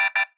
alarm Mixdown 1.mp3